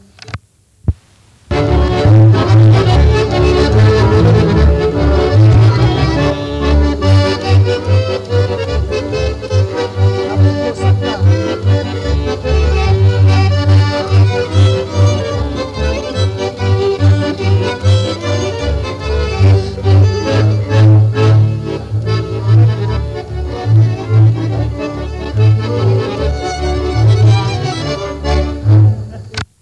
Nagranie archiwalne